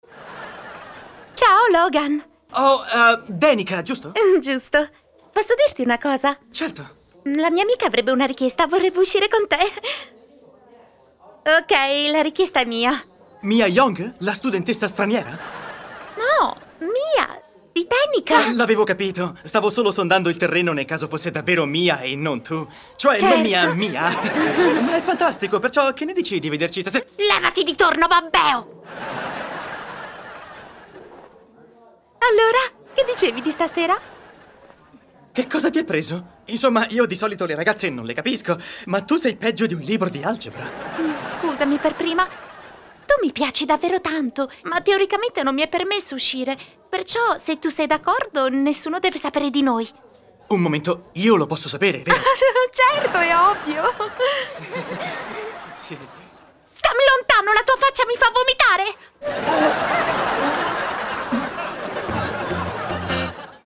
Female
Microphone: Neumann U87 Ai
Audio equipment: Mic pre amp: Universal Audio LA-610 Mk II A/D converters: Aurora Lynx